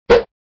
character_portal_in.wav